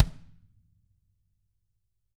Index of /90_sSampleCDs/ILIO - Double Platinum Drums 1/CD4/Partition A/TAMA KICK D